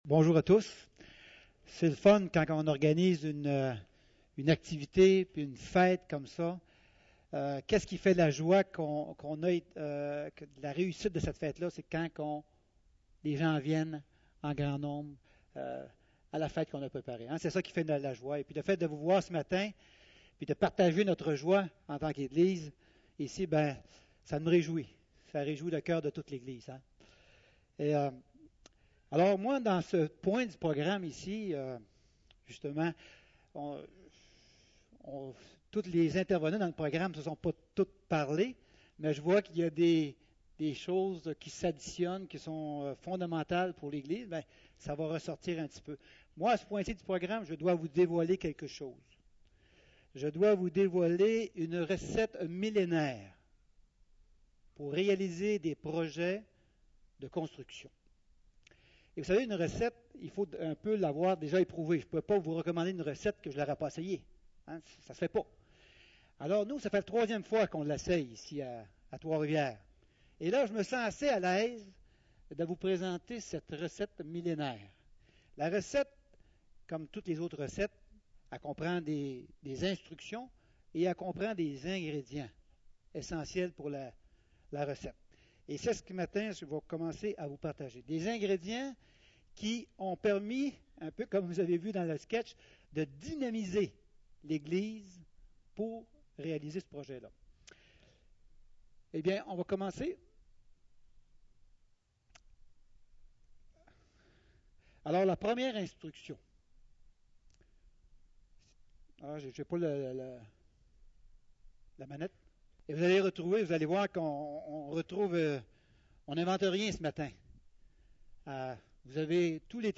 Portes ouvertes | Inauguration de l'agrandissement